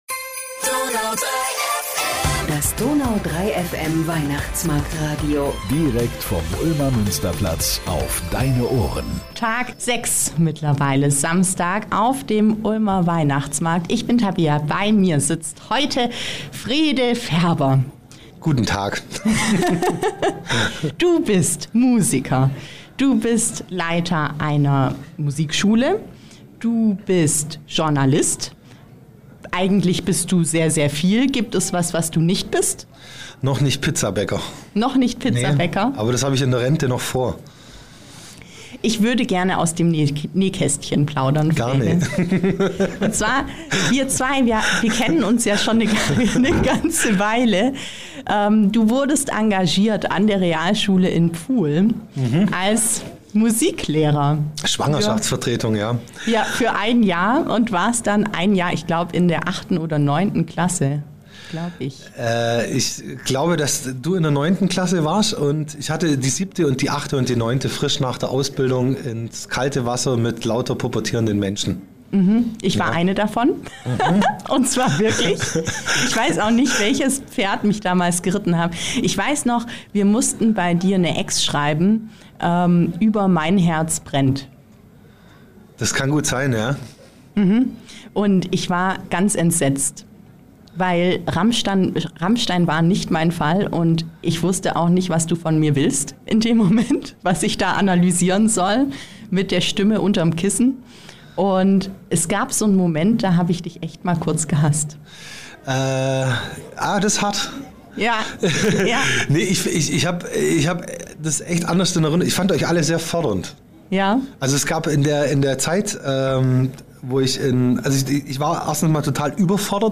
und gesungen wurde auch noch..